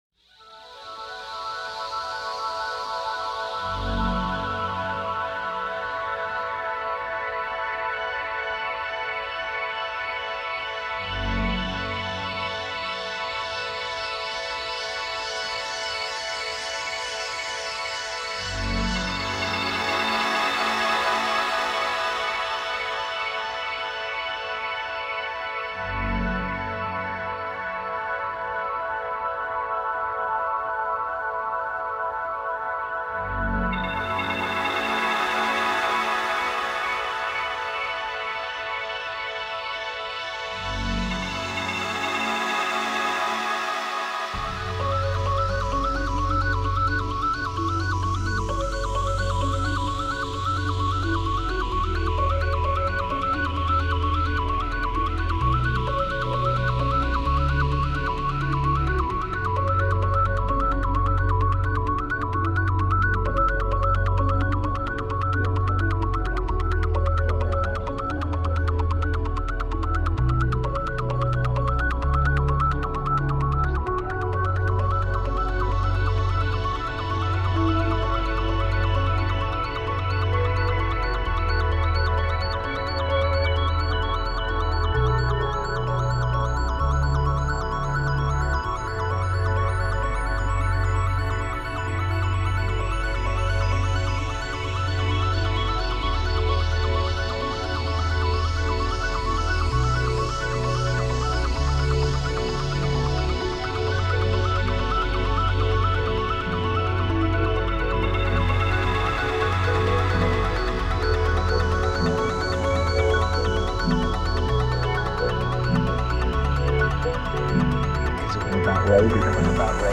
recorded using Ableton Live.